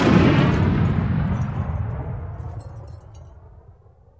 Index of /90_sSampleCDs/AKAI S6000 CD-ROM - Volume 1/SOUND_EFFECT/EXPLOSIONS